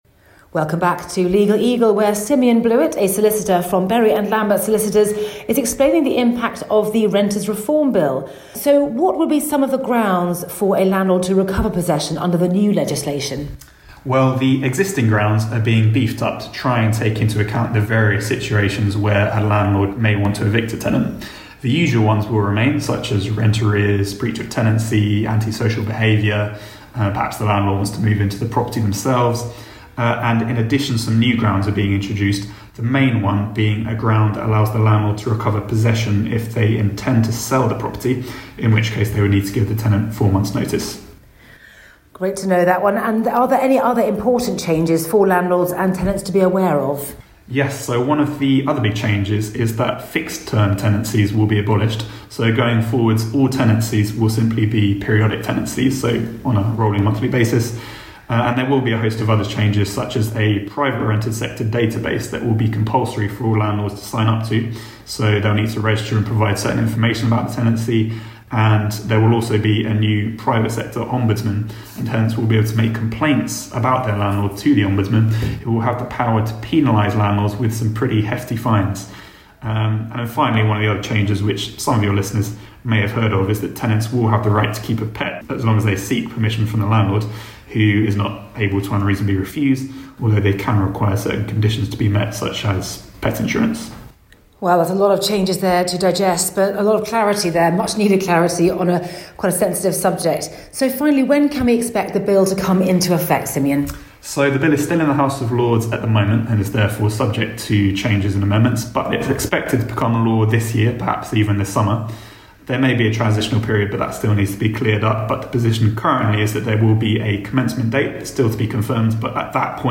More from INTERVIEW REPLAYS